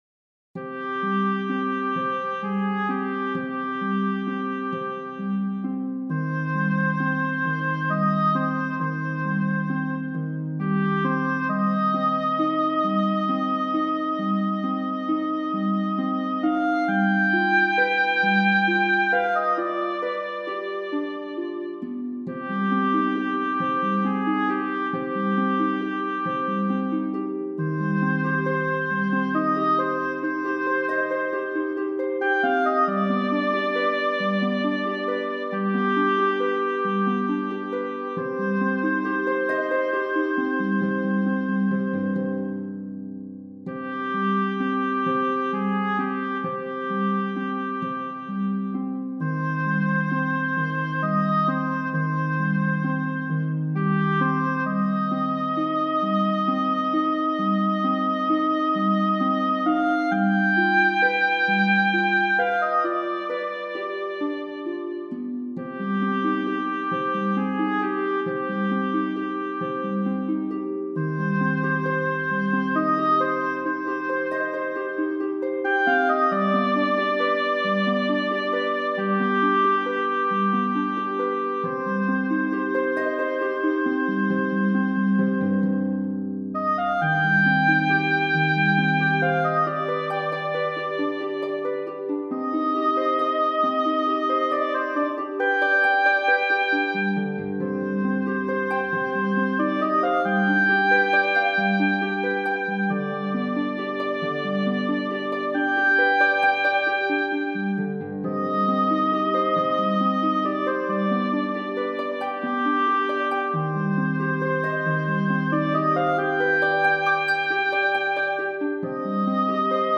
田舎風小曲集） Flute / Harp